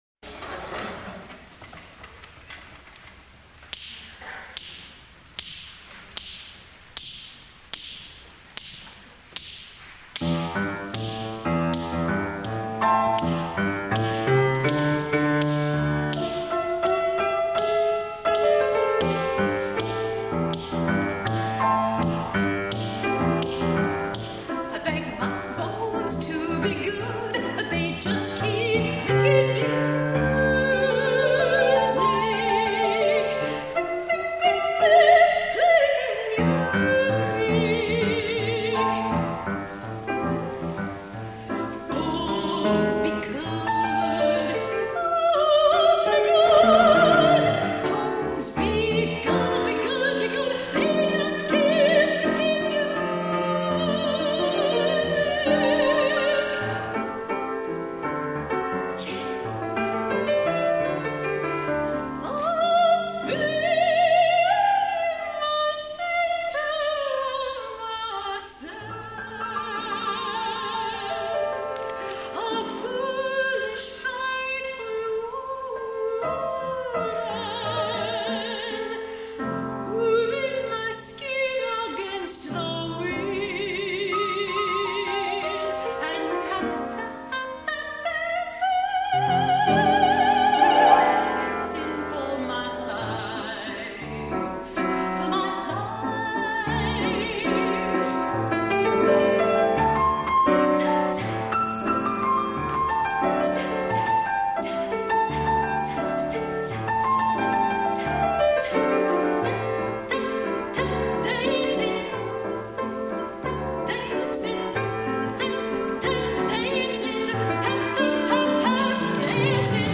for Soprano and Piano (1997)